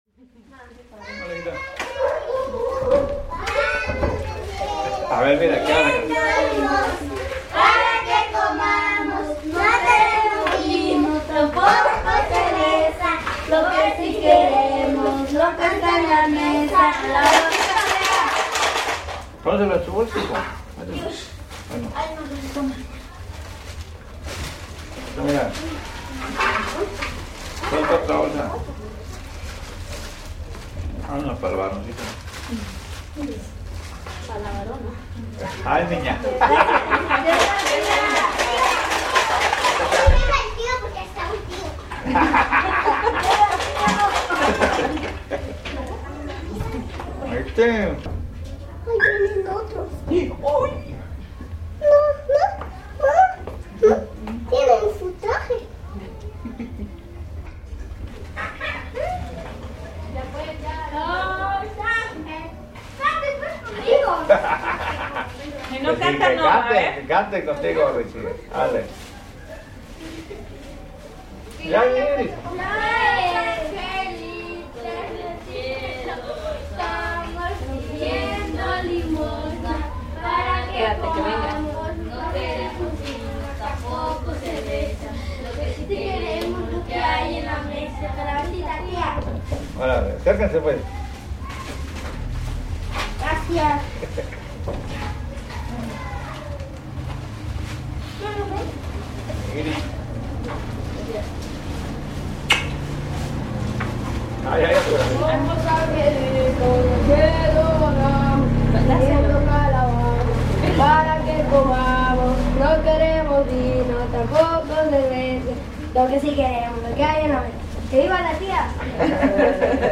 Este dulce le da nombre a una de las actividades que realizan los niños del lugar, quienes adaptando a sus costumbres la tradición del Hallowen, se disfrazan y salen a las calles con una lata llena de piedras pidiendo "Calabacita tía".
En esa tarde las calles de la ciudad se inundan de bullicios y risas.
Este es el registro de una de las casas donde cada año, sin excepción, los señores preparan bolsas con variados dulces para regalárselos a los niños.